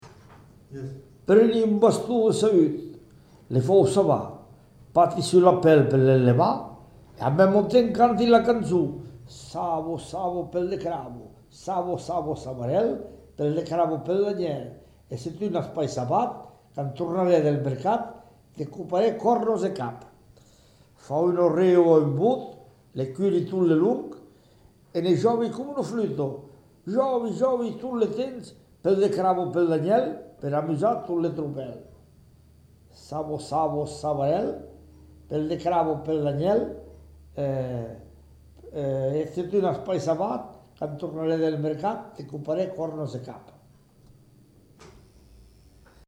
Lieu : Bressols
Genre : témoignage thématique
Instrument de musique : cloche d'église